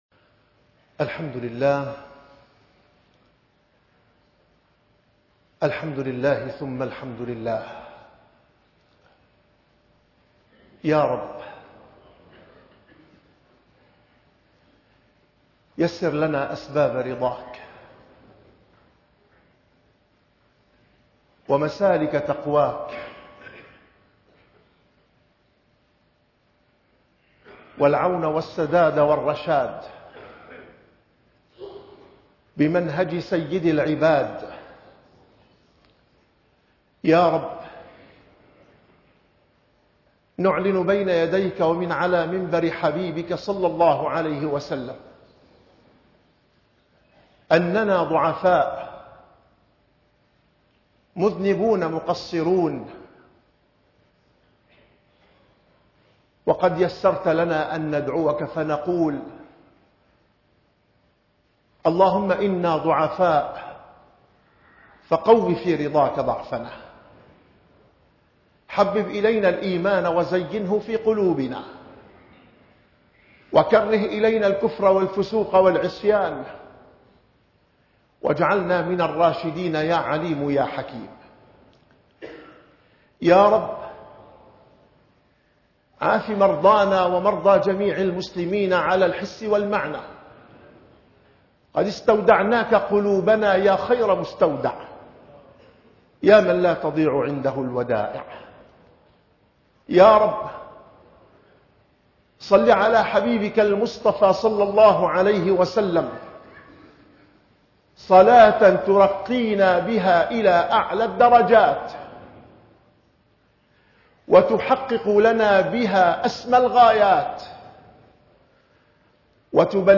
- الخطب